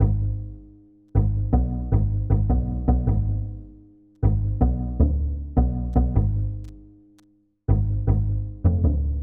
Tag: 78 bpm Hip Hop Loops Bass Loops 1.55 MB wav Key : Unknown